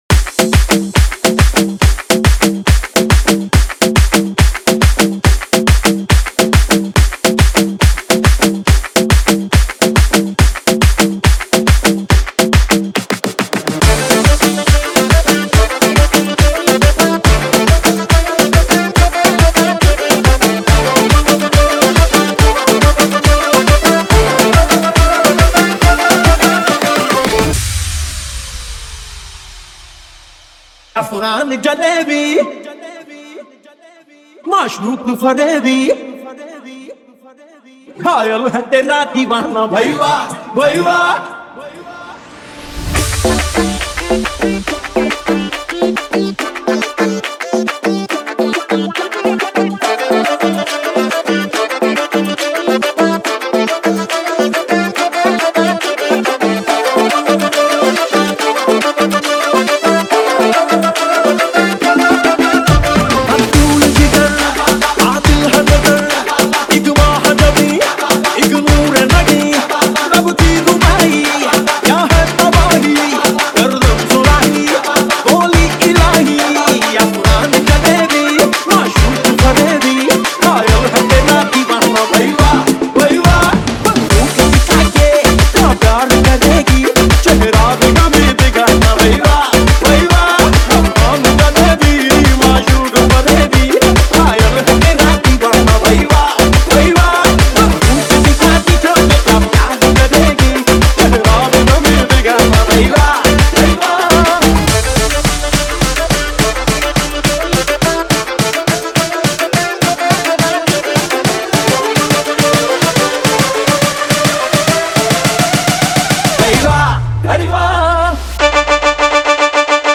• Genre: Bollywood EDM Remix
• Powerful bassline that enhances club sound systems
• Smooth build-ups and impactful drops
• DJ-friendly intro and outro for easy mixing
• High BPM suitable for dance and fitness playlists